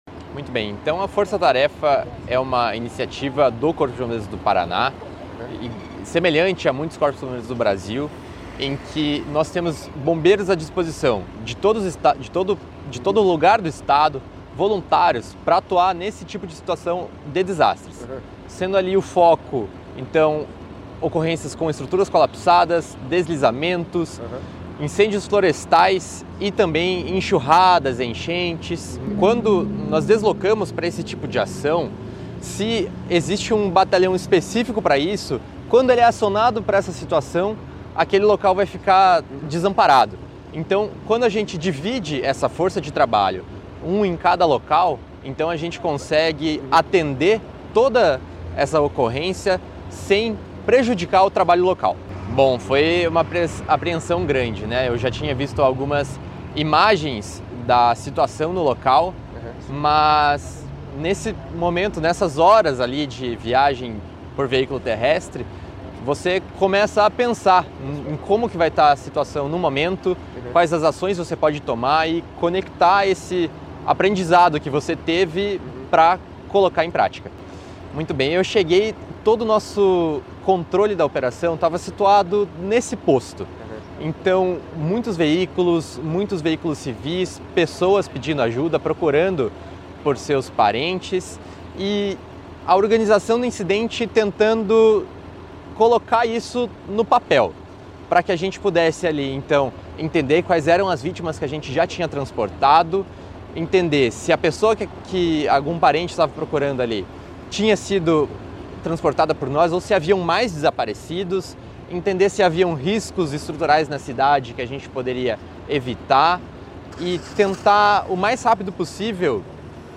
Sonora do primeiro-tenente do Corpo de Bombeiros do Paraná